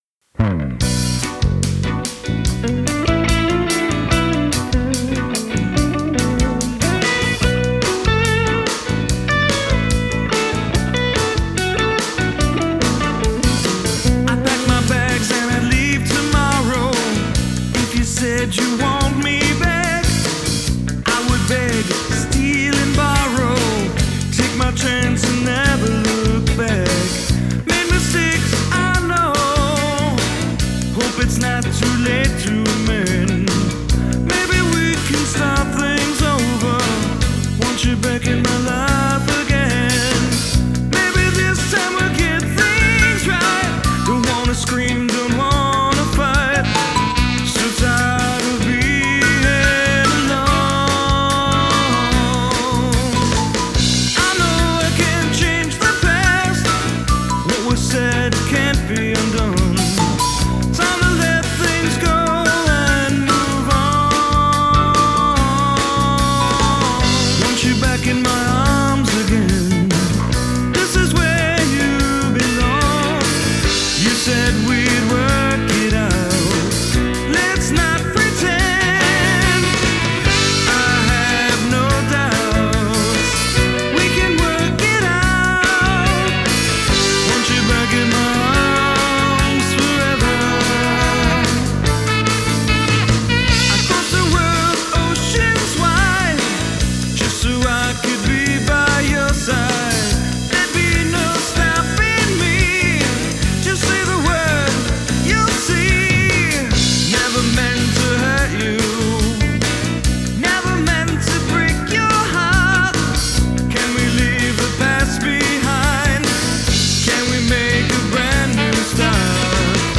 This version is a little less wet than the original version, which IMO suits the song better.
The saxophone is icing on the cake!
And that sax sounds soooo good!
Nice crisp and punchy sound...
Probably a tad of EQ here and there, and I usually use some tape emulation on drums too, to create a more saturated sound.